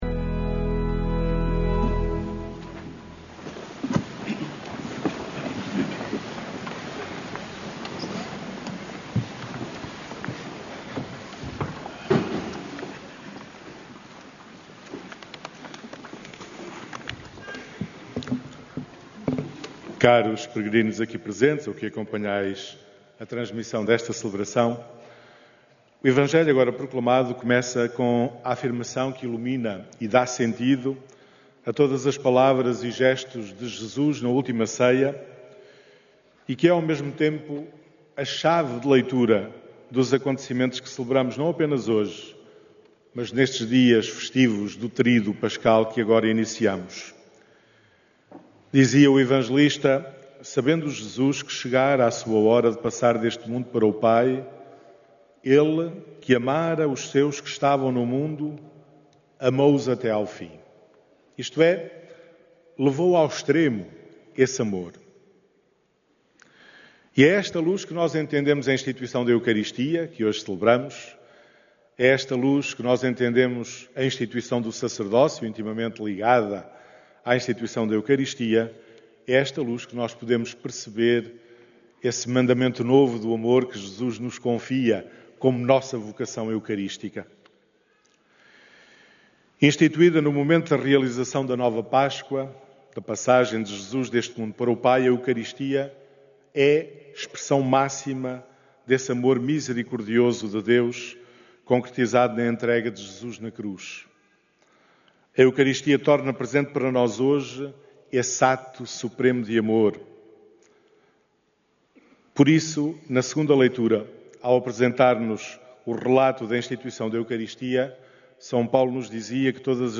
A Missa Vespertina da Ceia do Senhor, celebrada na tarde desta Quinta-Feira Santa na Basílica da Santíssima Trindade, marcou o início do Tríduo Pascal.
homilia